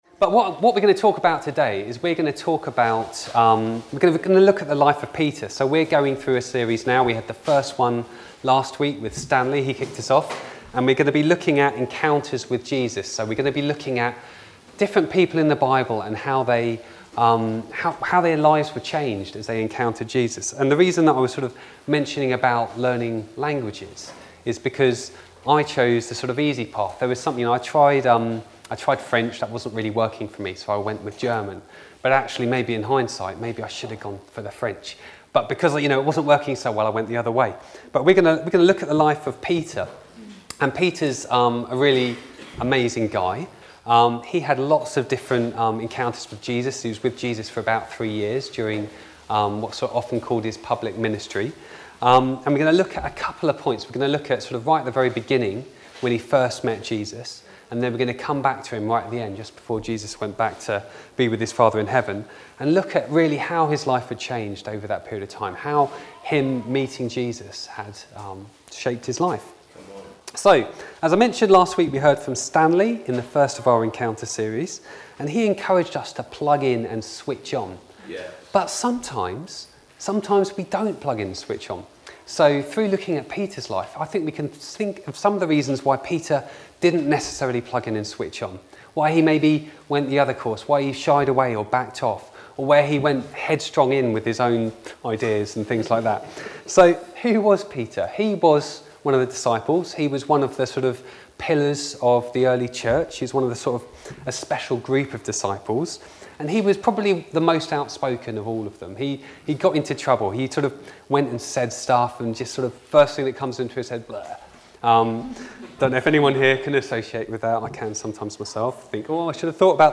Download Encounters with Jesus part two | Sermons at Trinity Church